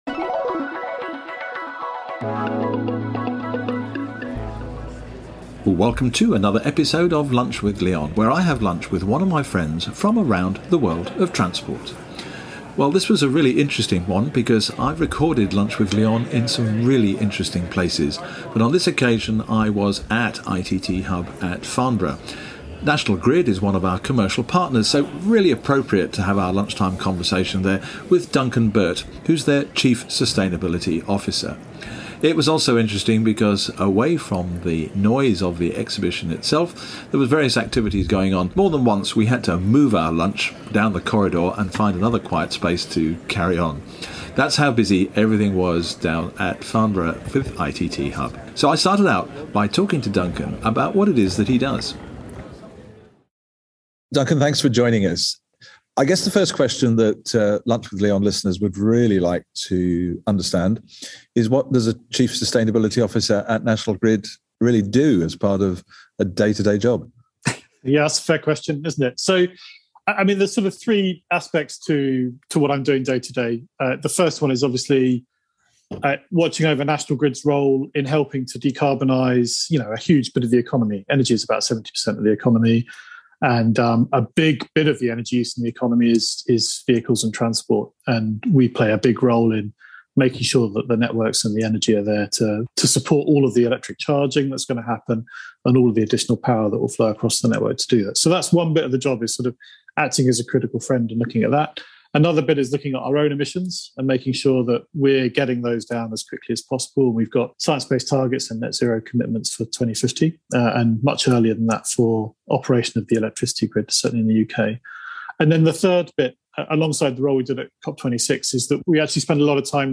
this time at ITT Hub in Farnborough